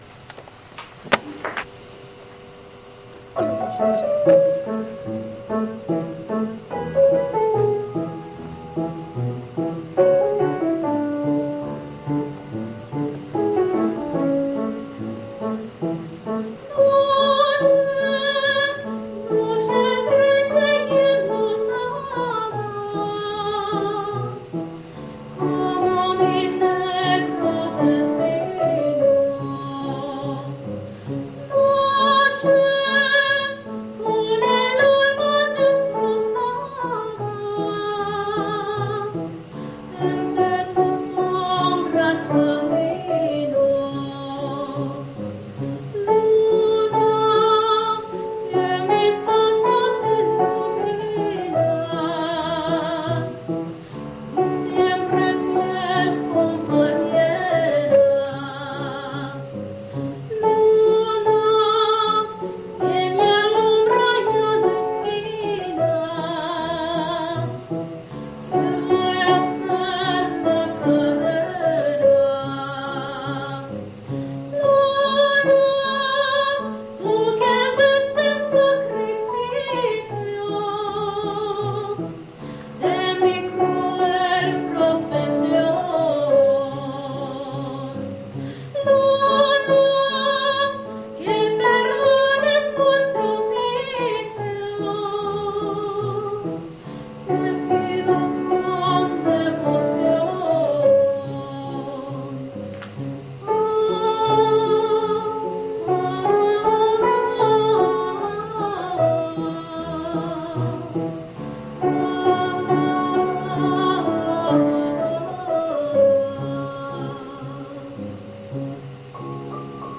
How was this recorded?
Going through my old cassette recordings from past performances, I've decided to share them here.